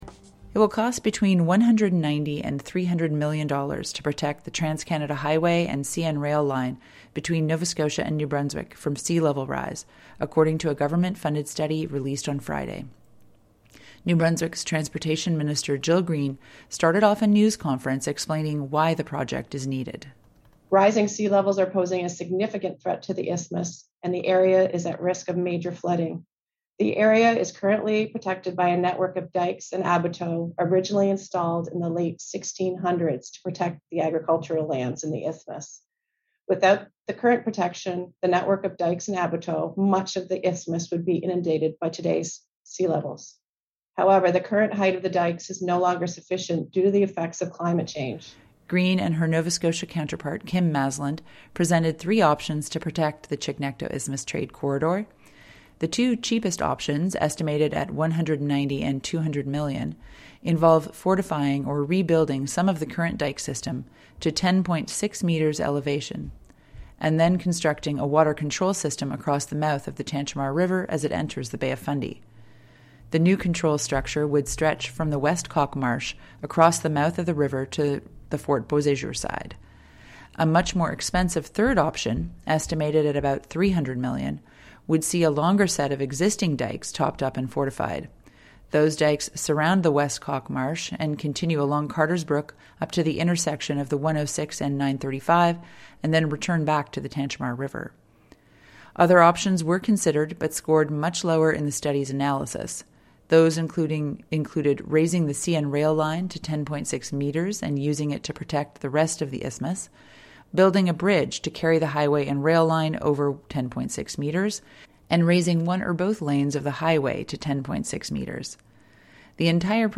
New Brunswick’s transportation minister Jill Green and her Nova Scotia counterpart Kim Masland presented three options to protect the Chignecto Isthmus trade corridor in a news conference Friday afternoon.
CHMA brings you highlights from the conference and reactions from two Sackville politicians, mayor Shawn Mesheau and MLA Megan Mitton.